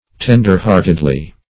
tender-heartedly - definition of tender-heartedly - synonyms, pronunciation, spelling from Free Dictionary
Ten"der-heart`ed*ly, adv.